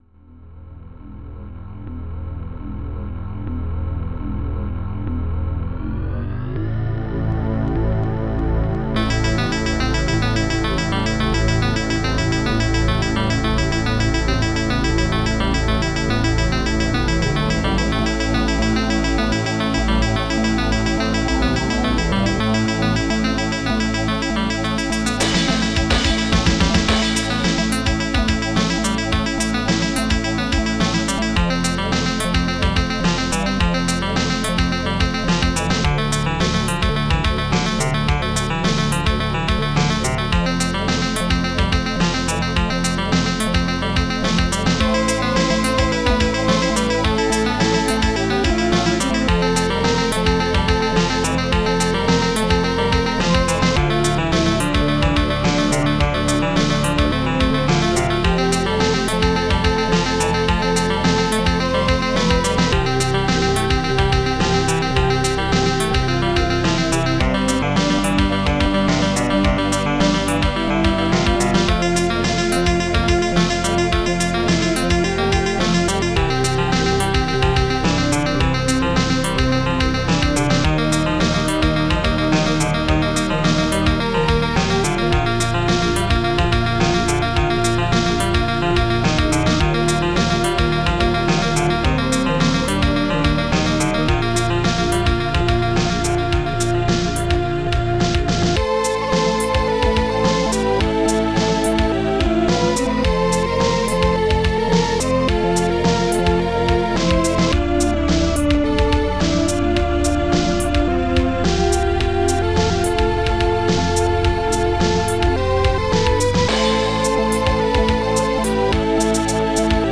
Protracker and family
ST-01:STEINWAY
ST-01:ANALOGSTRING
ST-05:SNARE14
ST-03:BASSDRUM10
ST-01:SHAKER
ST-02:CRASH
ST-01:STRINGS6
ST-01:HIHAT2